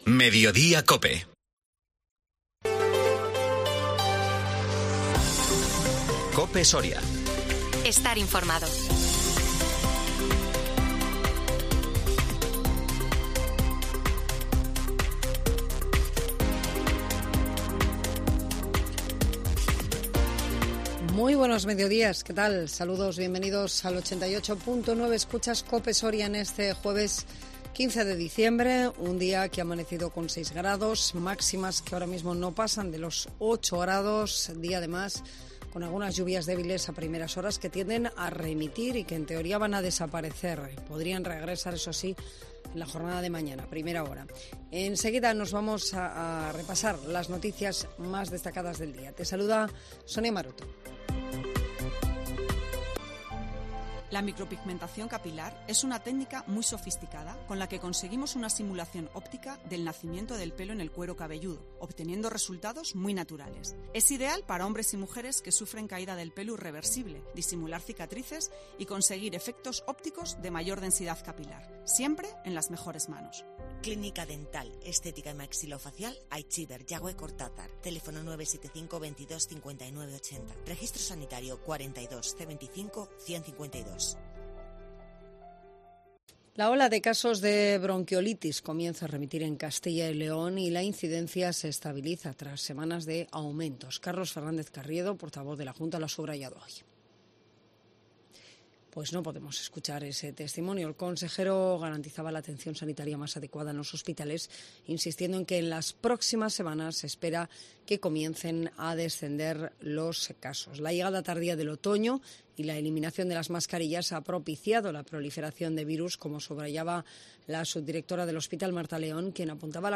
INFORMATIVO MEDIODÍA COPE SORIA 15 DICIEMBRE 2022